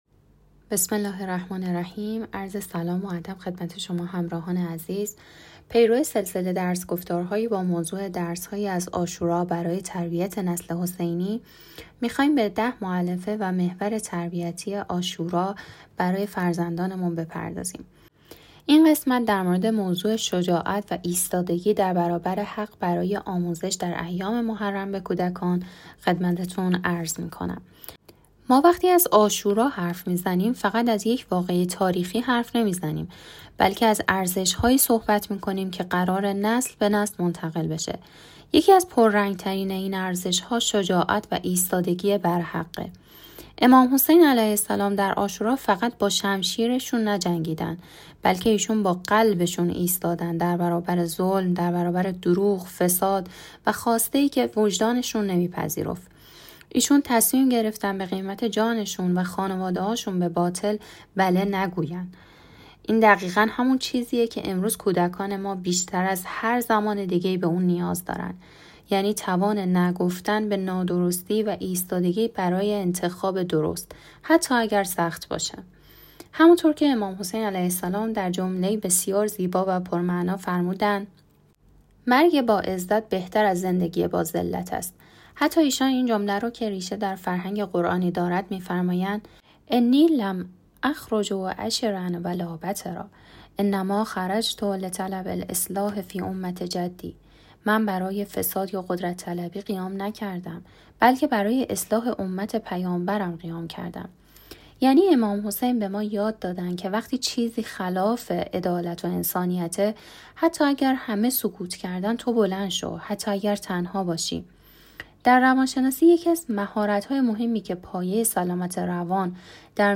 در سلسله درس‌گفتار‌های «درس‌هایی از عاشورا برای تربیت نسل حسینی»، می‌خواهیم به ۱۰ مؤلفه و محور تربیتی عاشورا برای فرزندانمان بپردازیم. در این قسمت، موضوع «شجاعت و ایستادگی در برابر حق» را برای آموزش به کودکان در ایام محرم بیان می‌کنیم.